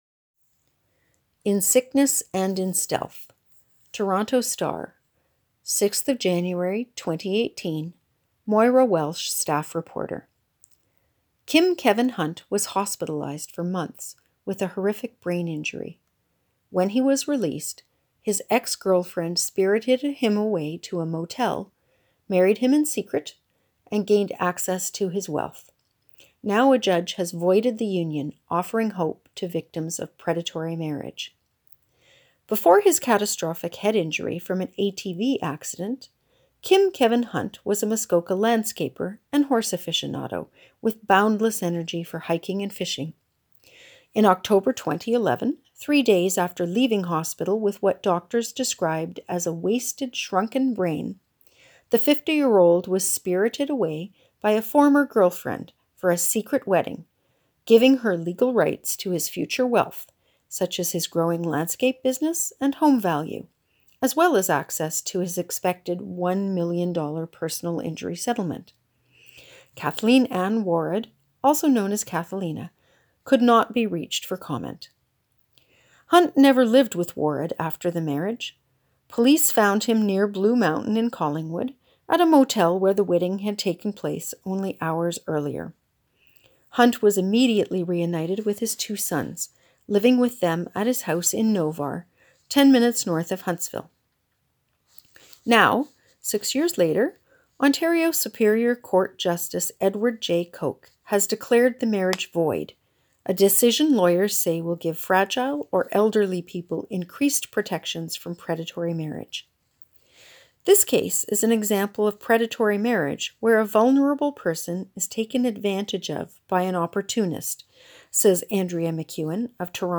If you would prefer to have the article read aloud to you, simply click the play button below.